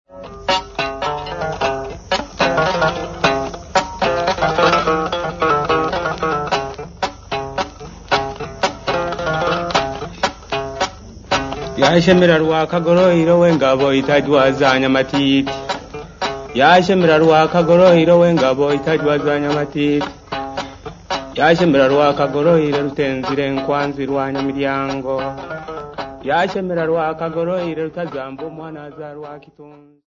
Indigenous folk music
Nanga seven string trough zither
Recitative
Chanted
Tanganyika (Tanzania)
accompanied by nanga seven string trough zither
Original format: 15ips reel
Hugh Tracey (Recorded by)